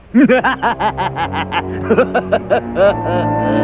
13) Evil Laugh
laugh.wav